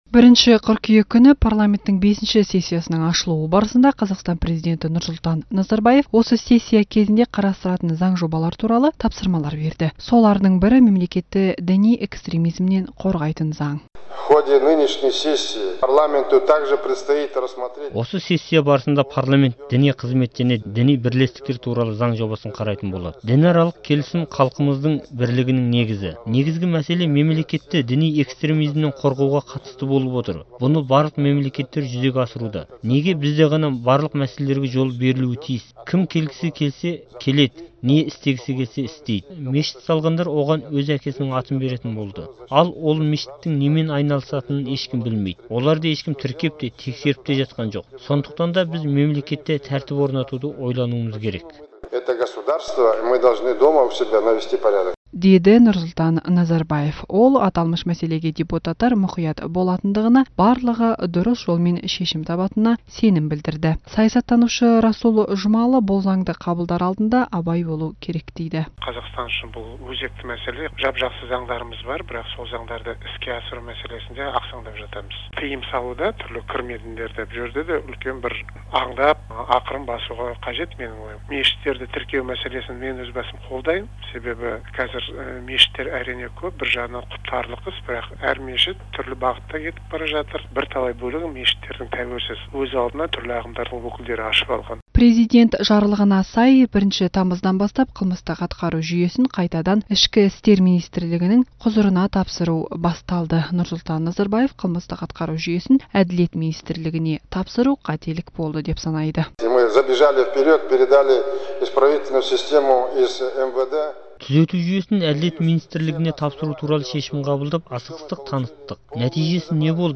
Назарбаевтың парламенттегі сөзін тыңдаңыз